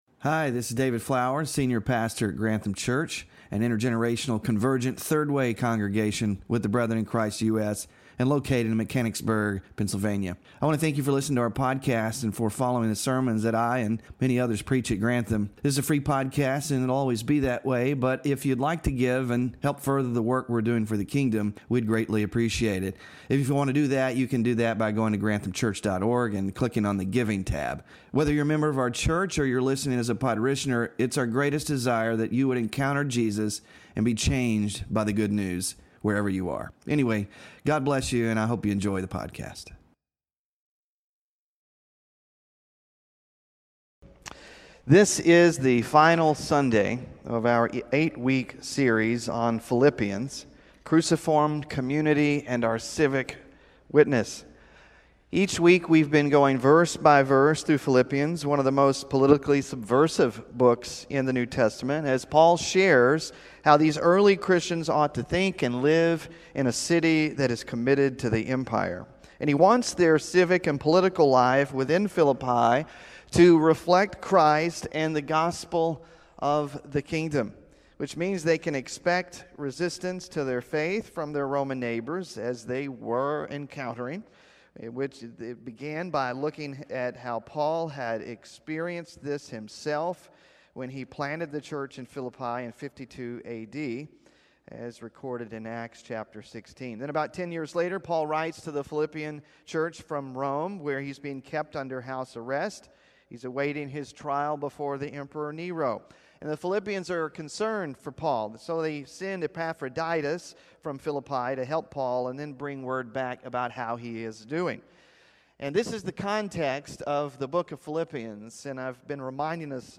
PHILIPPIANS SERMON SLIDES (8TH OF 8 IN SERIES) SMALL GROUP DISCUSSION QUESTIONS (9-29-24) BULLETIN (9-29-24)